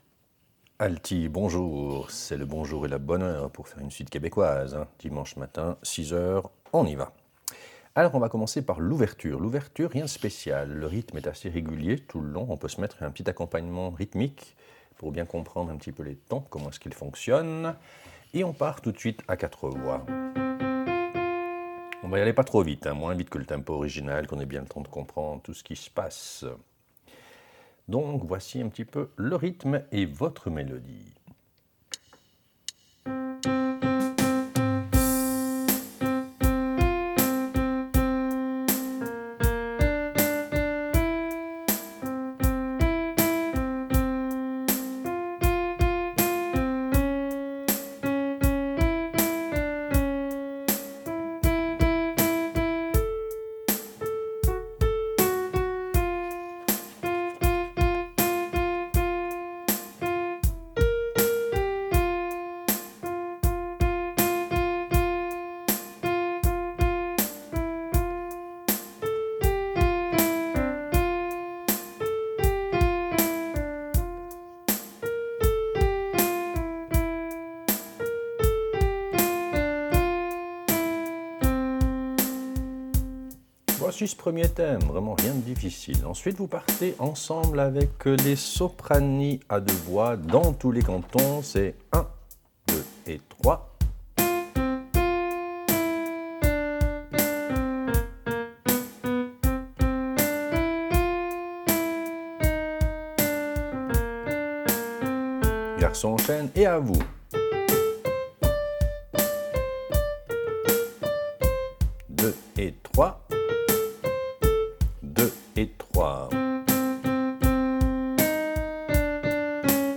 Répétition SATB4 par voix
Alto